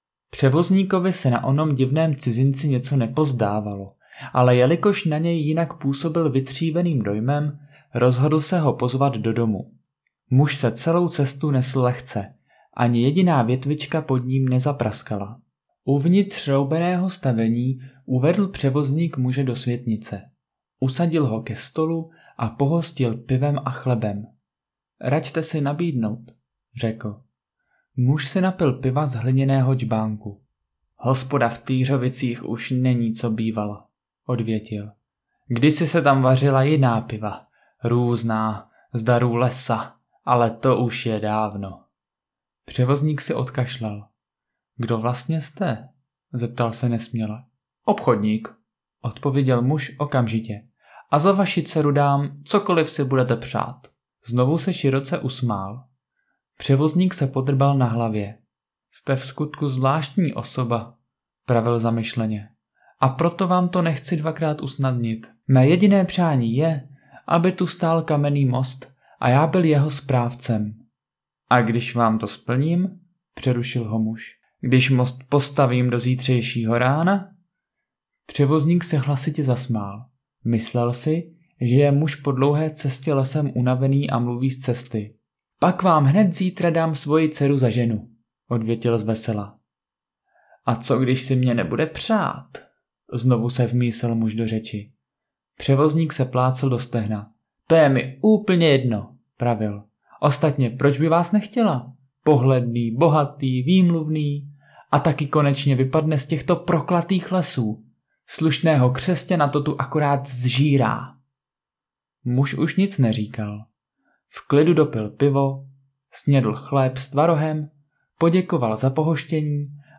Ukázka z audioknihy (část kapitoly Most přes řeku):